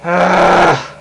Grunt Sound Effect
Download a high-quality grunt sound effect.
grunt-3.mp3